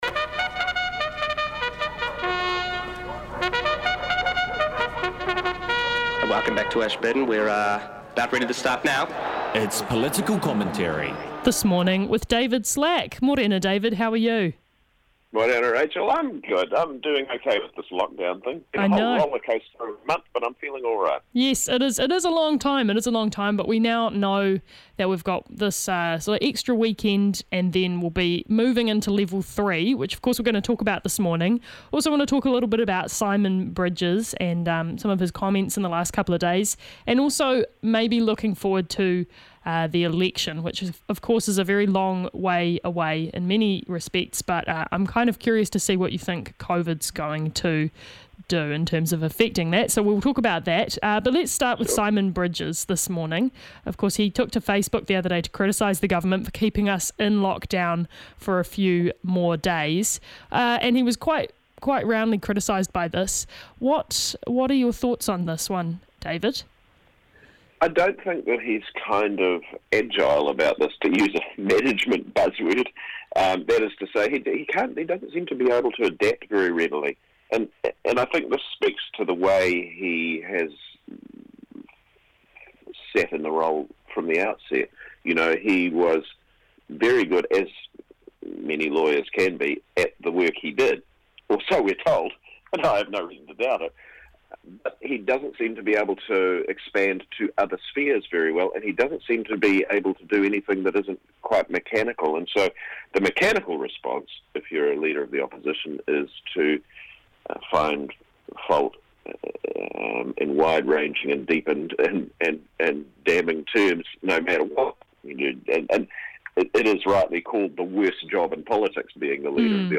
A rotating cast of the finest political minds offer up commentary on the big topics of the week.